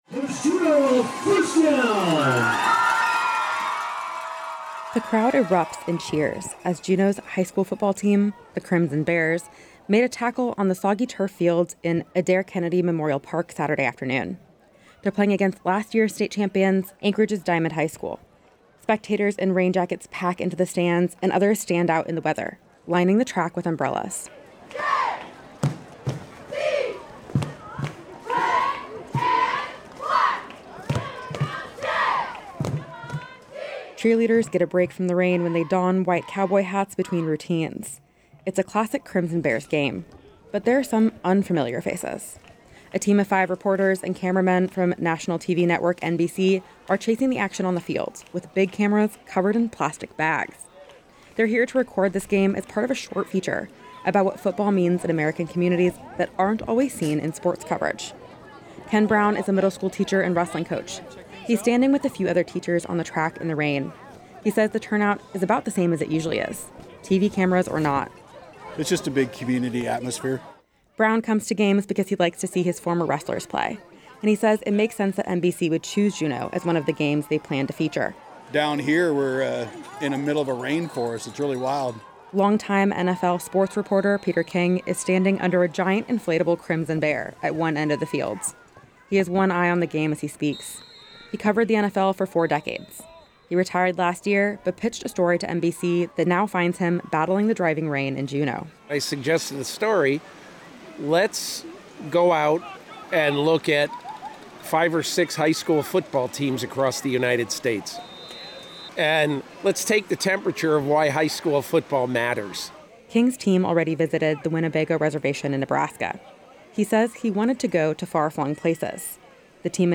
The crowd erupted in cheers as Juneau’s high school football team made a tackle on the soggy turf field in Adair-Kennedy Memorial Park on Saturday afternoon.